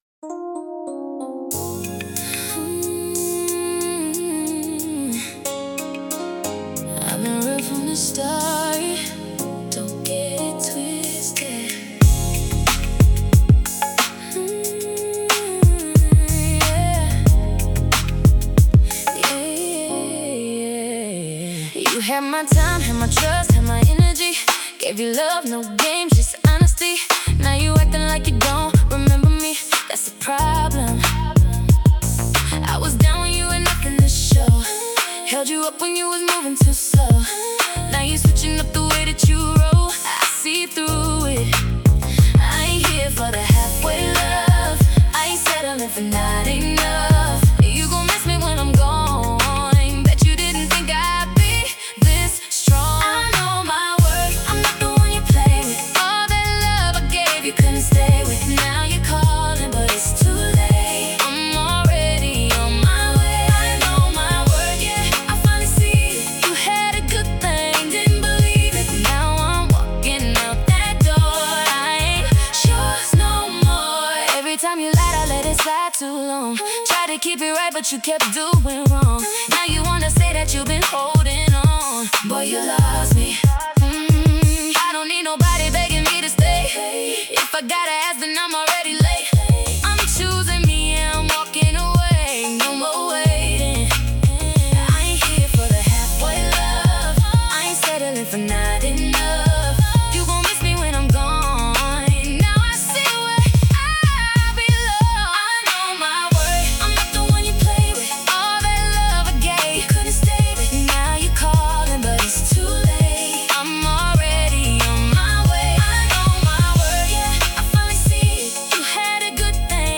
Intimate | Romantic 80 BPM